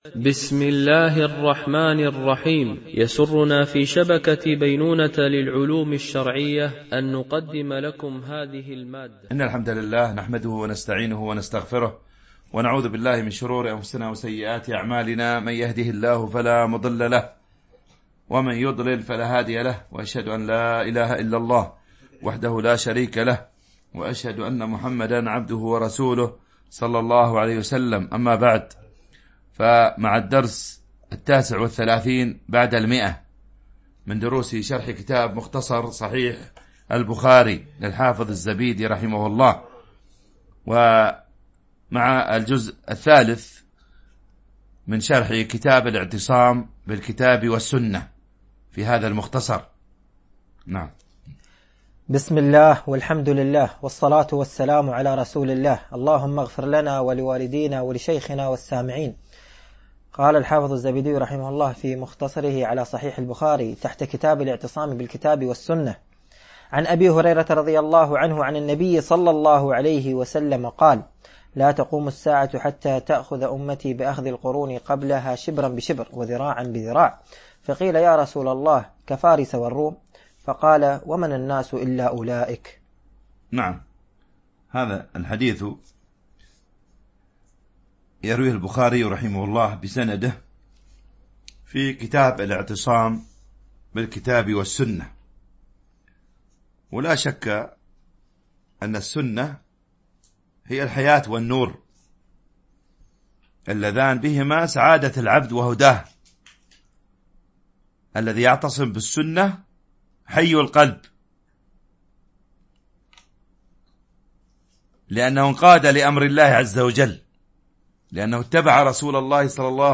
التنسيق: MP3 Mono 22kHz 70Kbps (VBR)